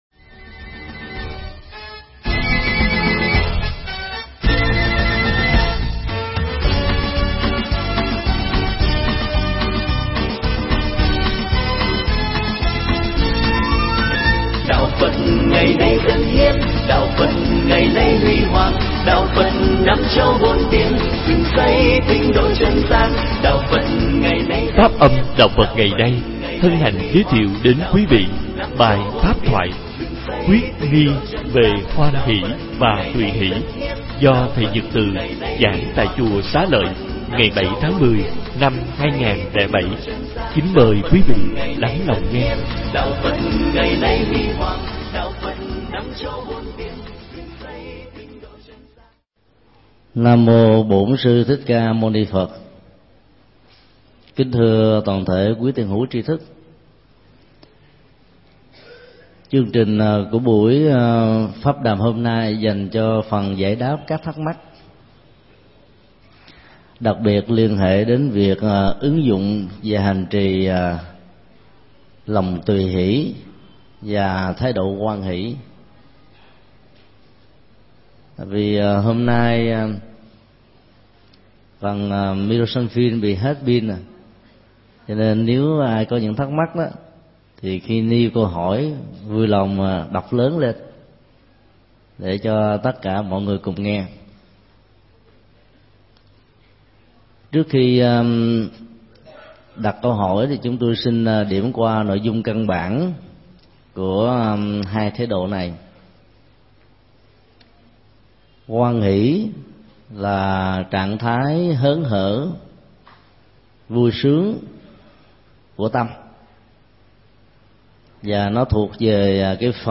Thuyết pháp
giảng tại Chùa Xá Lợi